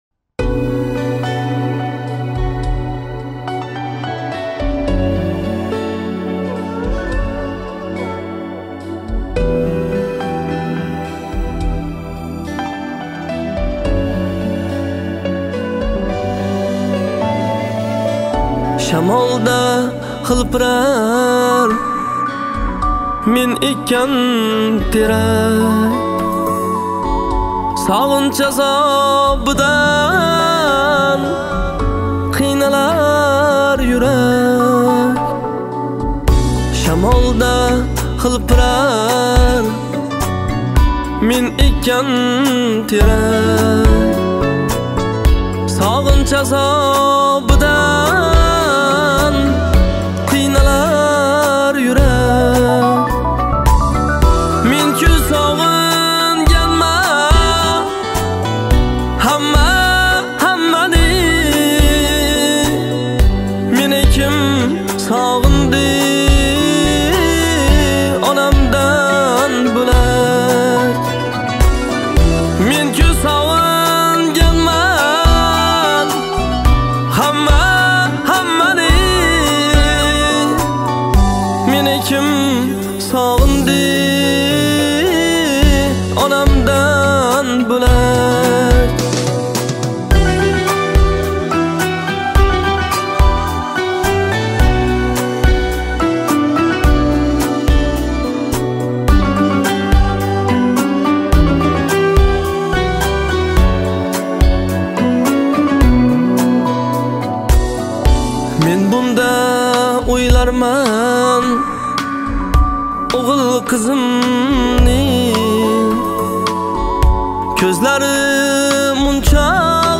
• Категория: Узбекские песни